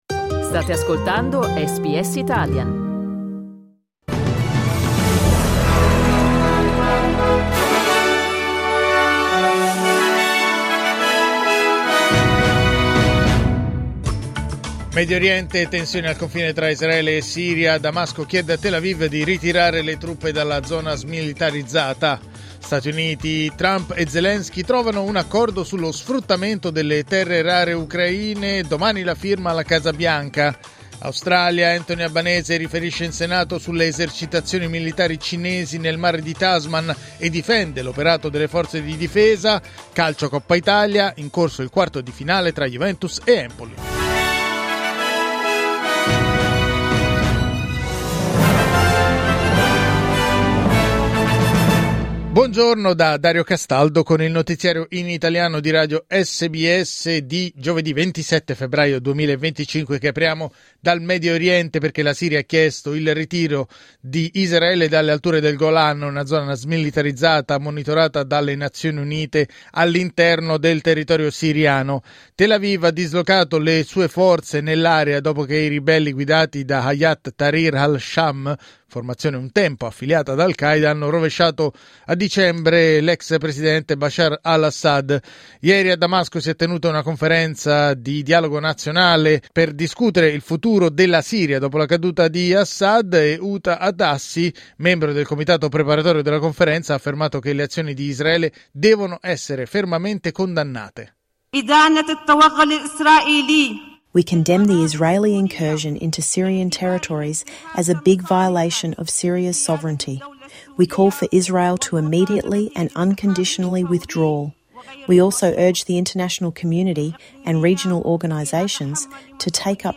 Giornale radio giovedì 27 febbraio 2025
Il notiziario di SBS in italiano.